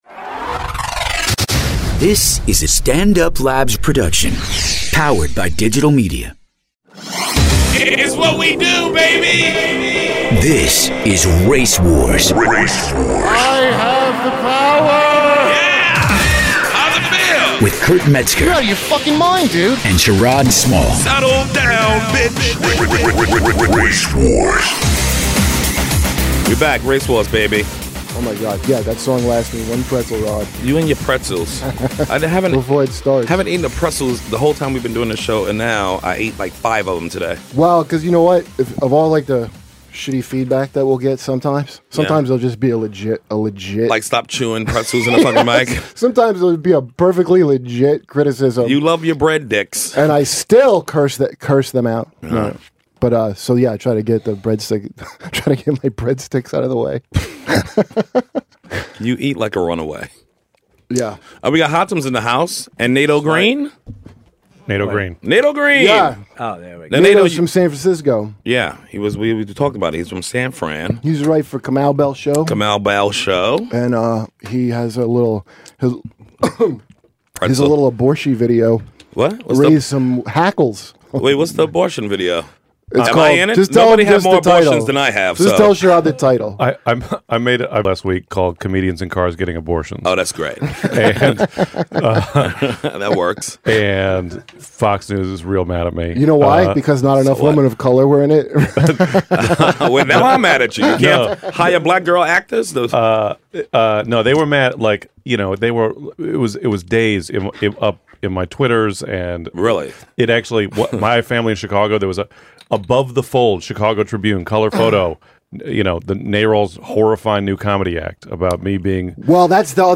LIVE from the Republican National Convention in Cleveland.